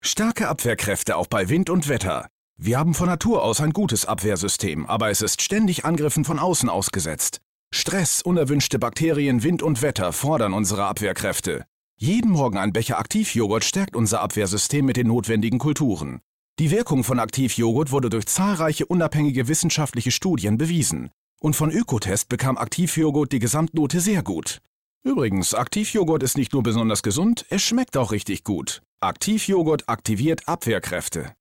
dunkel, sonor, souverän
Mittel plus (35-65)
Commercial (Werbung)